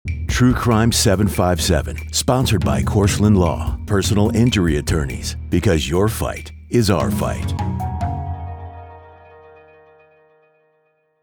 Male
Adult (30-50), Older Sound (50+)
My voice is warm, textured, and engaging, conveying a wide range of emotions.
Television Spots
1007TrueCrimeTVBumbermp3.mp3